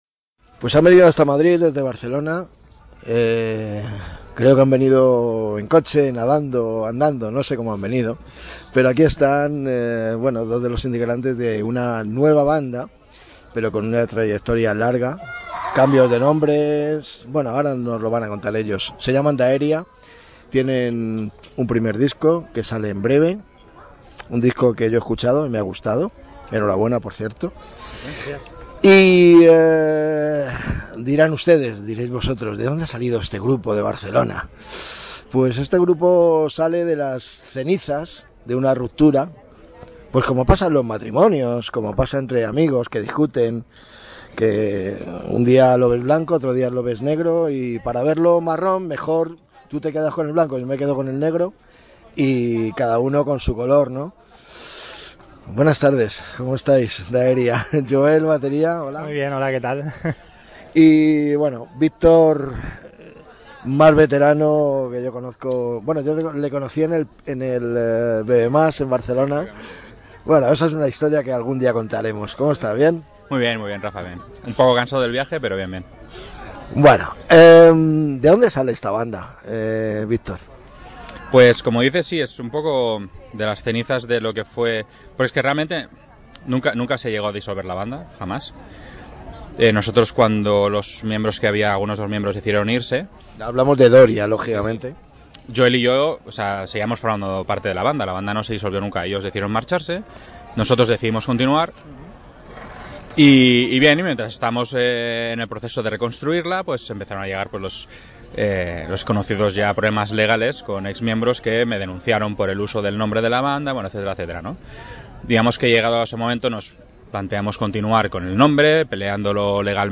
Esta es la entrevista.